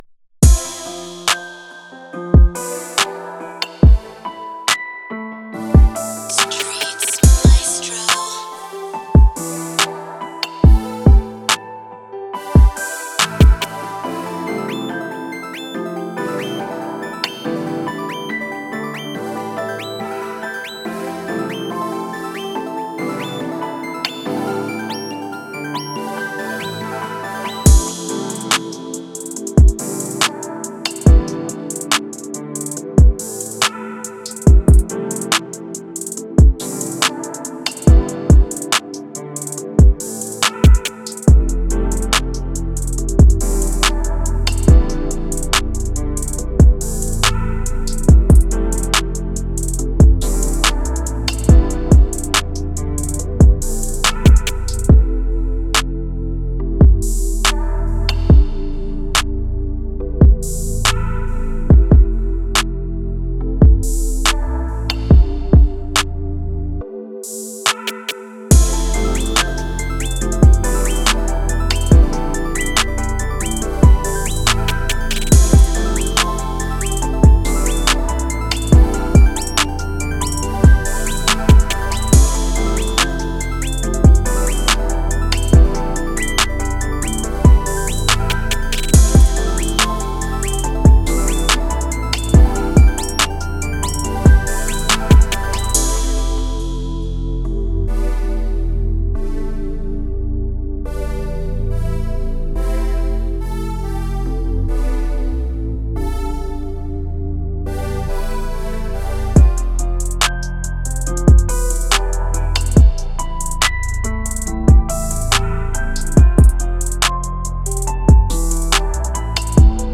Moods: Intimate, laid back, smooth
Genre: R&B
Tempo: 141
BPM 147
is a intimate, laid back, smooth beat.